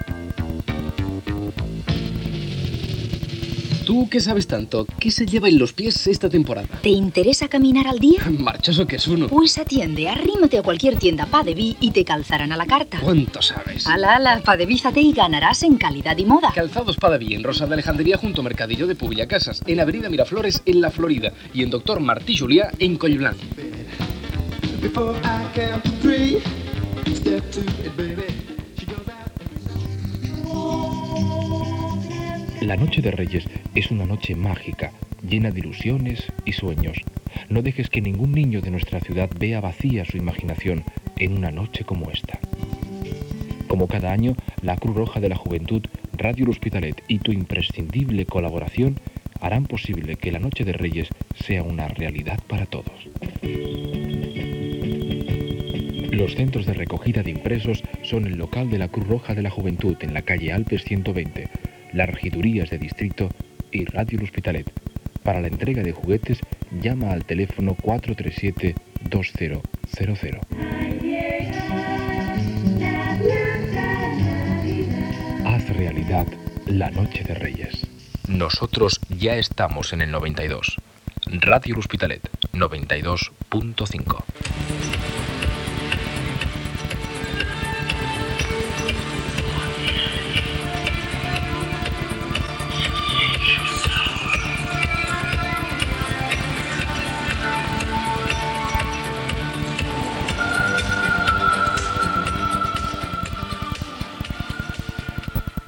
d6c801fc17c595af9077f55c33a8e49e302de32d.mp3 Títol Ràdio L'Hospitalet Emissora Ràdio L'Hospitalet Titularitat Pública municipal Descripció Publicitat, campanya de recollida de joguines, identificació.